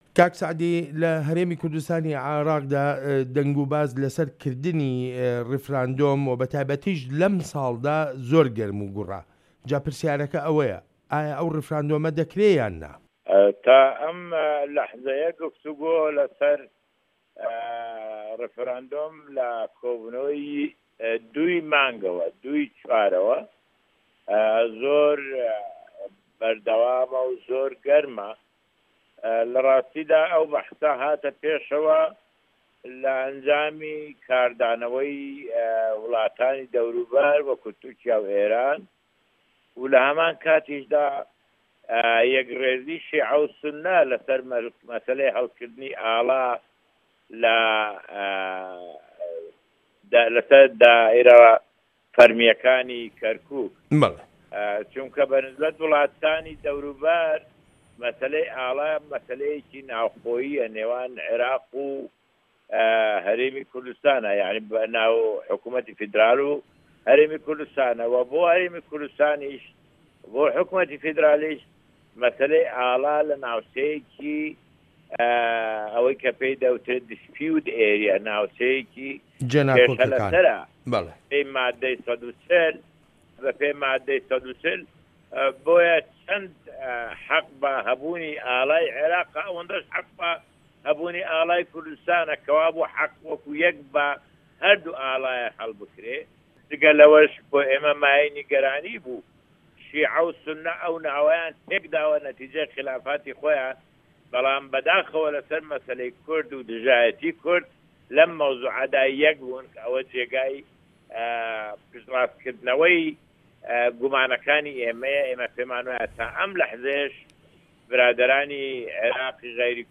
وتووێژ لەگەڵ سەعدی ئەحمەد پیرە